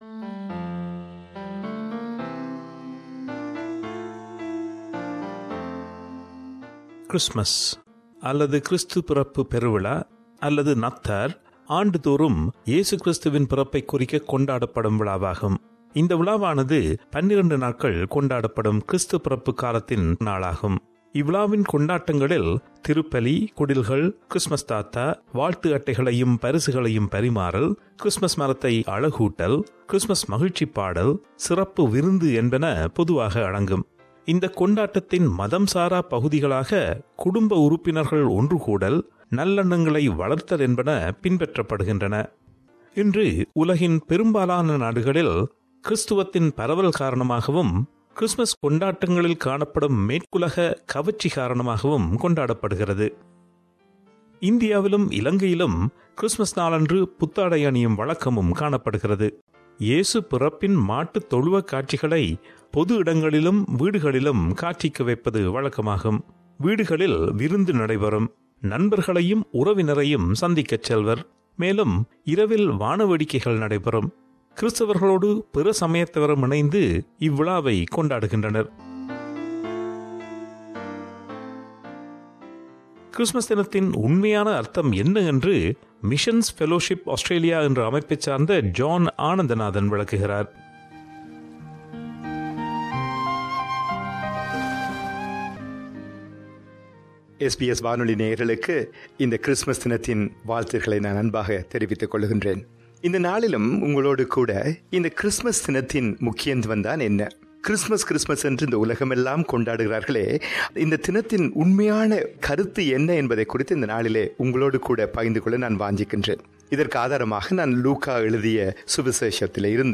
Also, a few of our listeners share their views on Christmas.